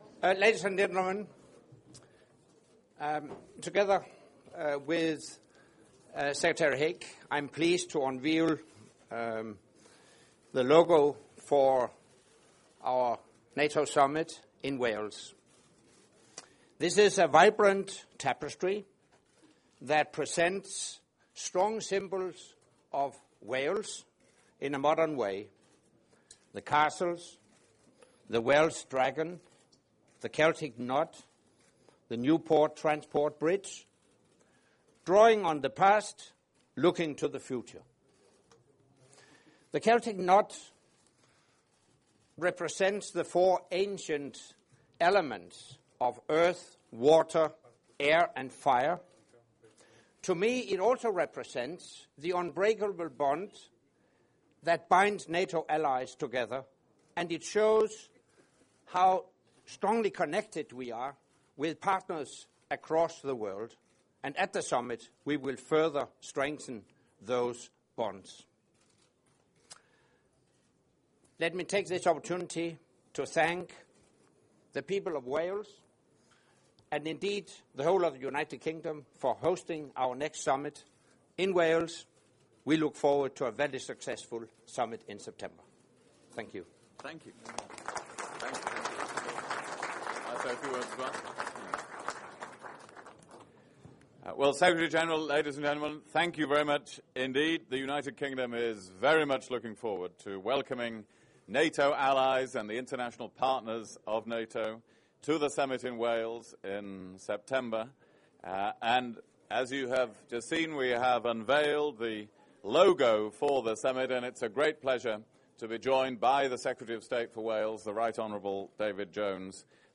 Remarks by NATO Secretary General Anders Fogh Rasmussen at the unveiling of the logo for the NATO Summit, Wales 2014